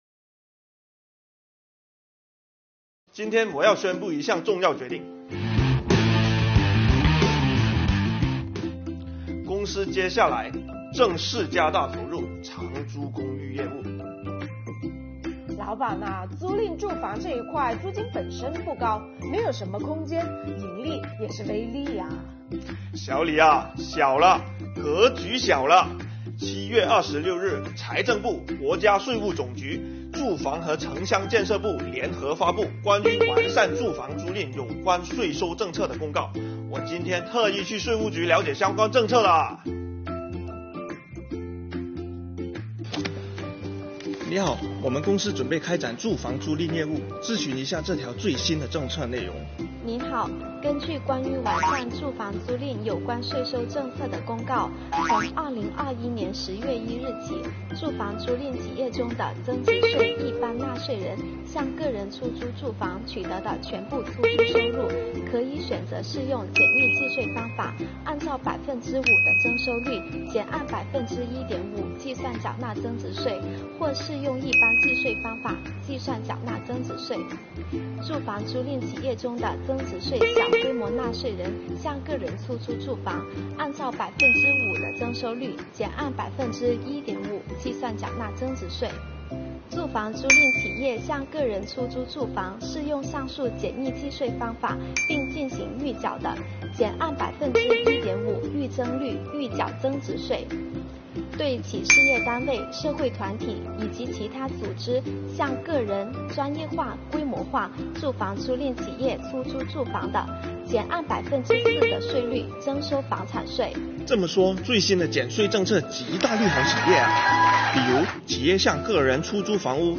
本作品以情景小短剧的方式展开，开篇运用动画特效的方式介绍人物，使人耳目一新。同时，整个作品节奏快，不拖沓，情节设定贴近生活，引发观者共鸣。